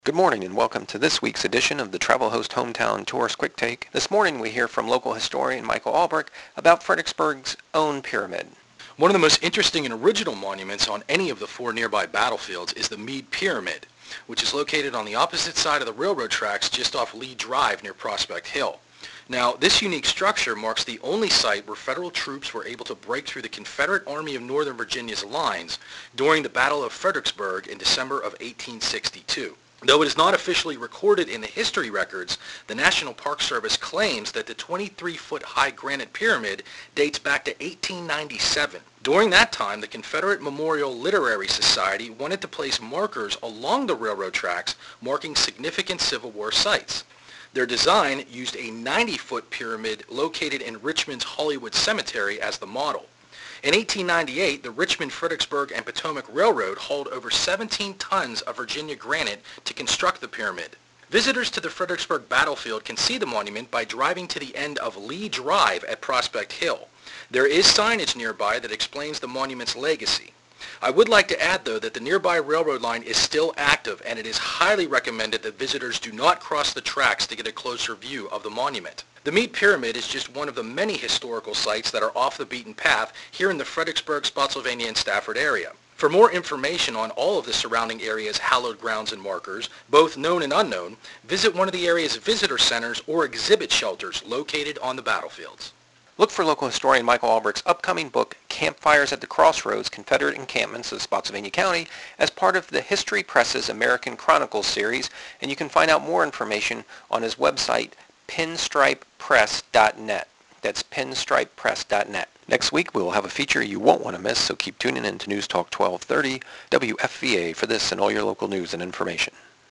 More radio spots